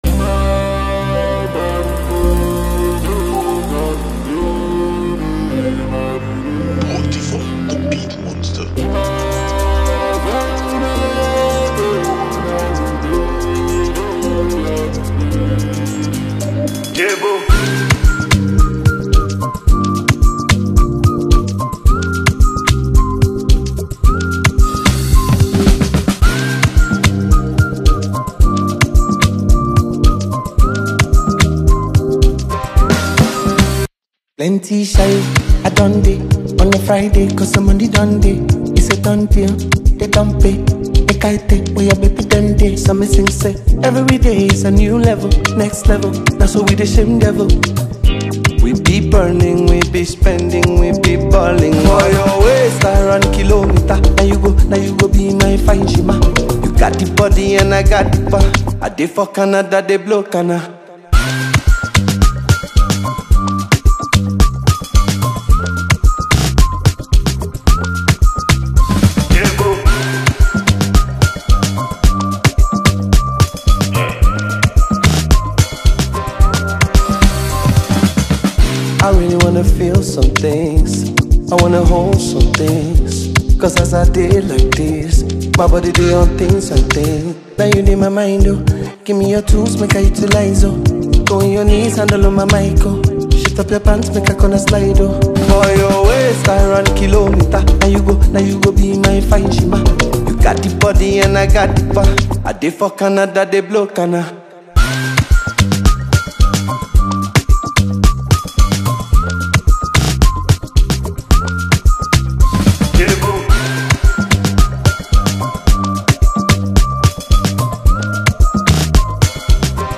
This catchy track is a must-add to your playlist.